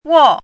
chinese-voice - 汉字语音库
wo4.mp3